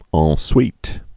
ɴ swēt)